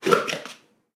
Abrir un tupperware
plástico
Cocina
Sonidos: Acciones humanas
Sonidos: Hogar